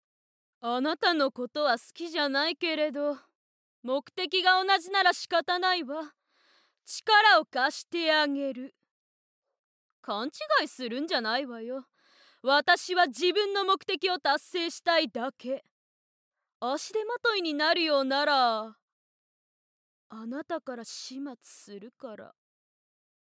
ボイスサンプル
強気な女性